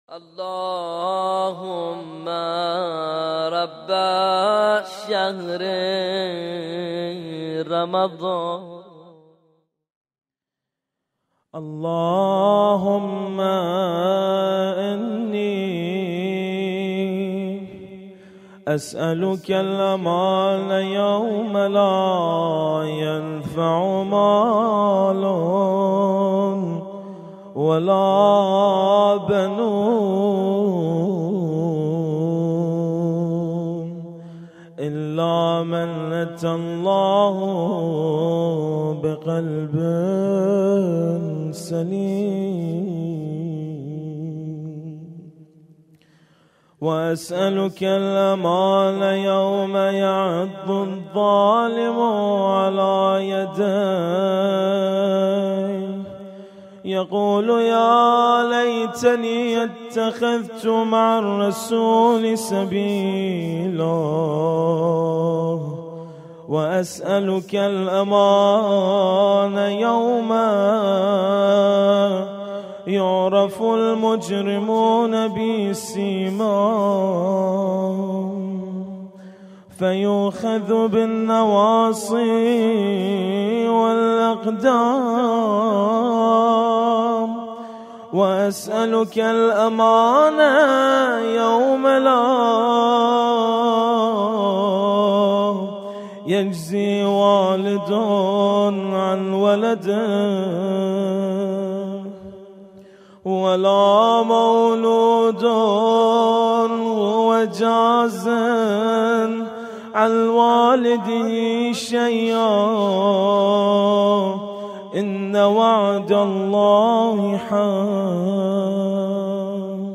قرائت مناجات أمير المؤمنين (ع)